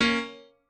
piano4_9.ogg